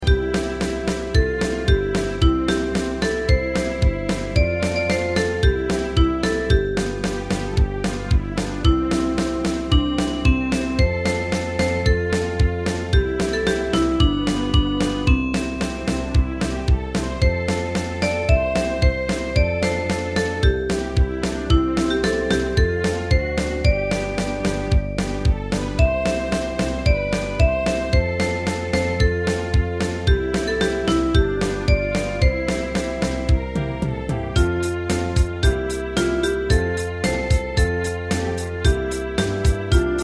大正琴の「楽譜、練習用の音」データのセットをダウンロードで『すぐに』お届け！
カテゴリー: ユニゾン（一斉奏） . タグ: 原曲が外国の曲 , 民謡 .